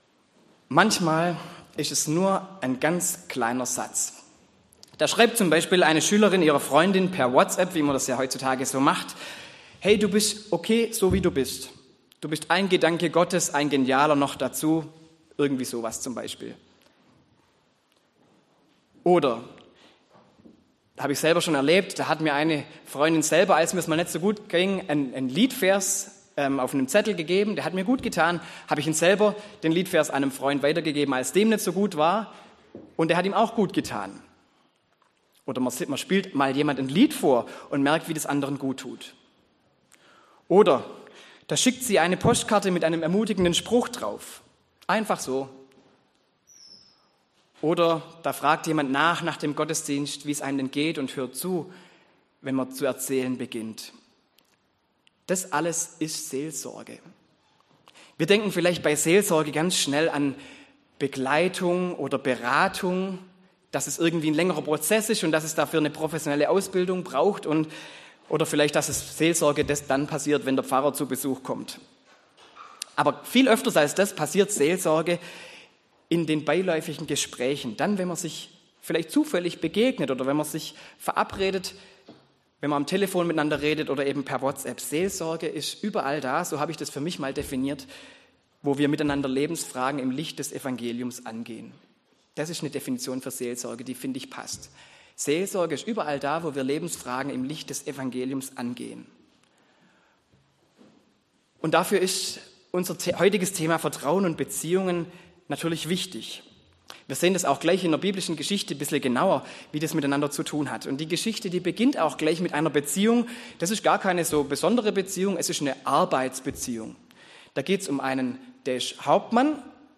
Predigt zur ORANGE - Themenreihe "Seelsorge im Alltag".